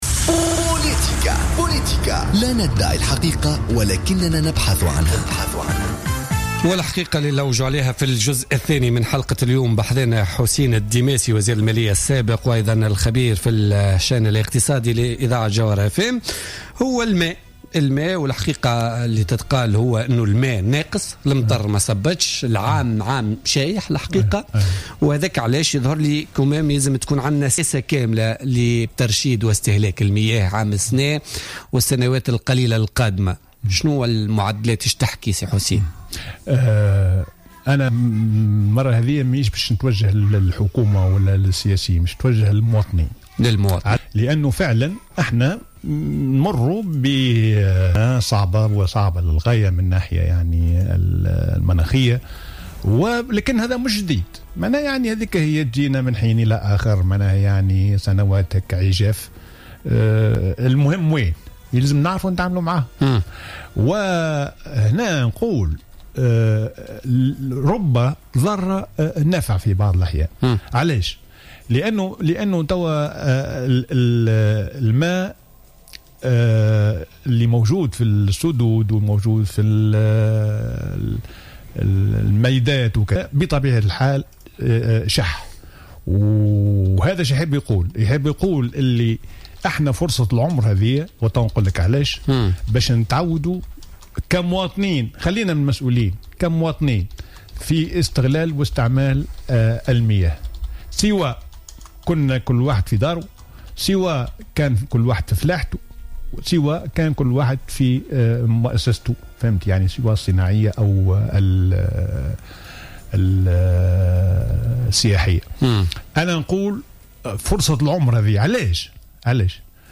قال حسين الديماسي الخبير الاقتصادي ووزير المالية السابق في تصريح للجوهرة أف أم في برنامج بوليتكا لليوم الجمعة 26 فيفري 2016 إن الحد الأقصى للتعبئة المائية في البلاد سيكون سنة 2020 وذلك حسب دراسة شاملة ومعمقة أجريت منذ نحو 25 سنة في تونس.